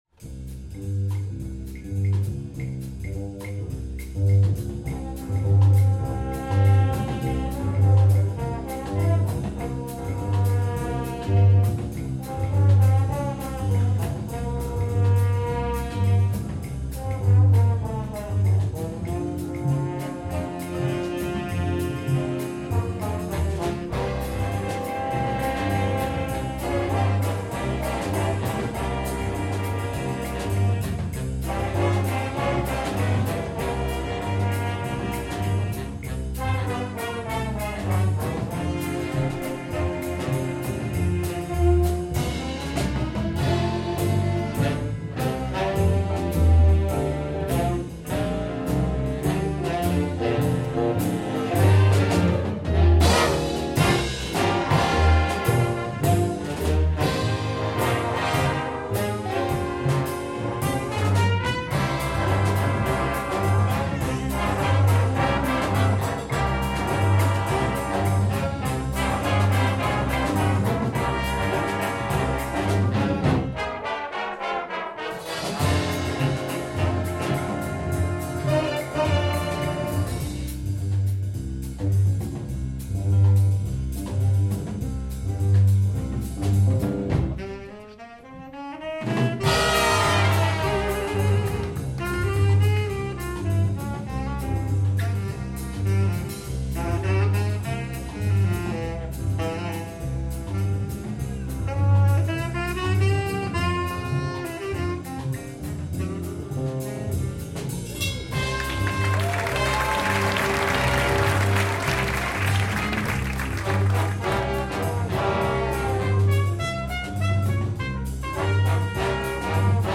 Big Band Sound aus Blankenese